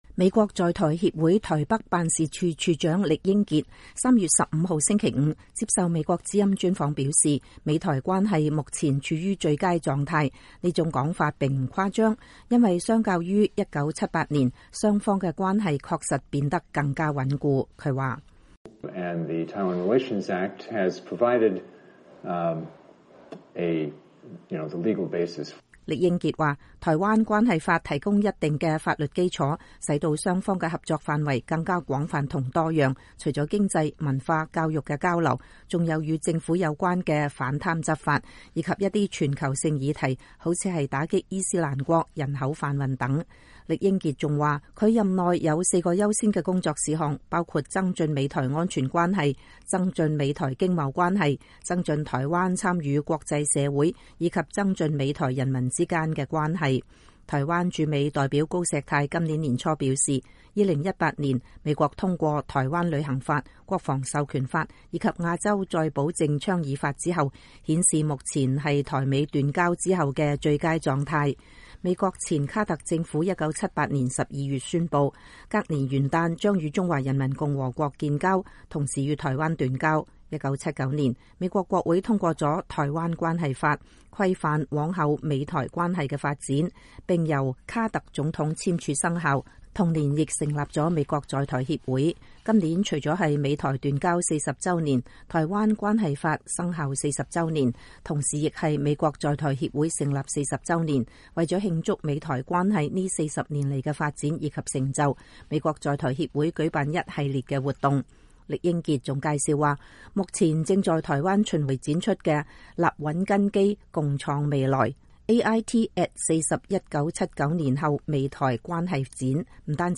今年是台灣關係法40週年，美國在台協會舉辦了一系列的慶祝活動，AIT台北辦事處長酈英傑在接受美國之音專訪時表示，相較於40年前，美台關係除了變得更加穩固，更處於最佳狀態。